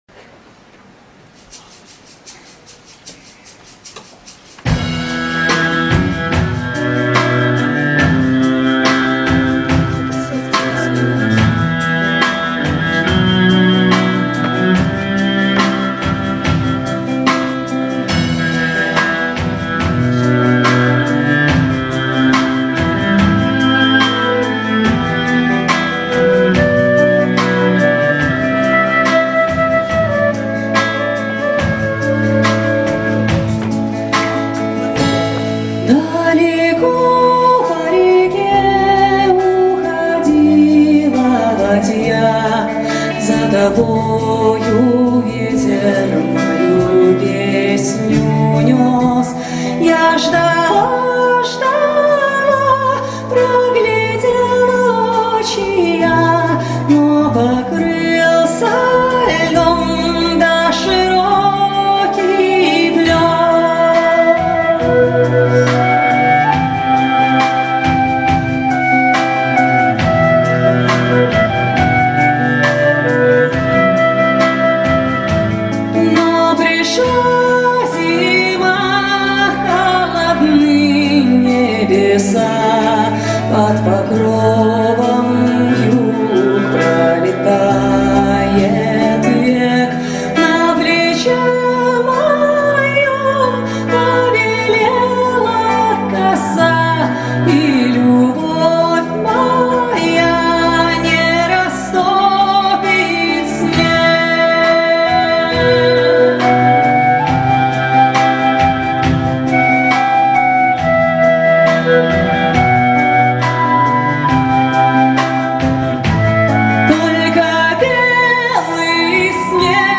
Рождественский концерт в Театре Эстрады